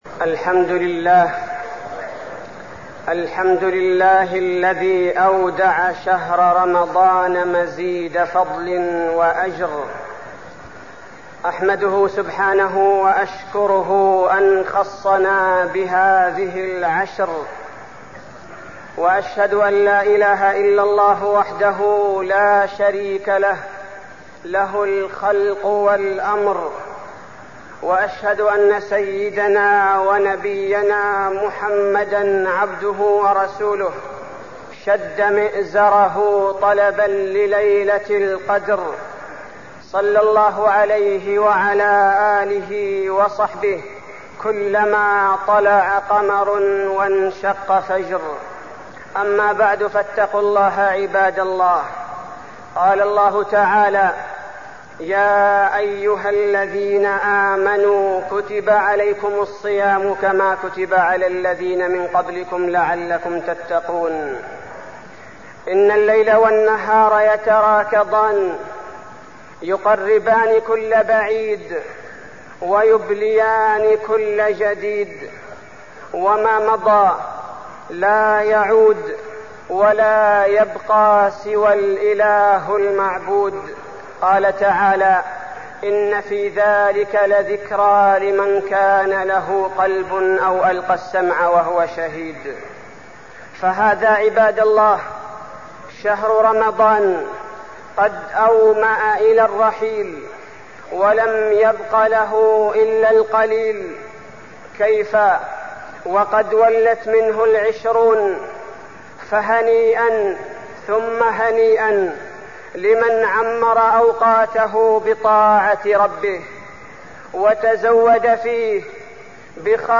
تاريخ النشر ٢٦ شعبان ١٤١٥ هـ المكان: المسجد النبوي الشيخ: فضيلة الشيخ عبدالباري الثبيتي فضيلة الشيخ عبدالباري الثبيتي فضائل العشر الأواخر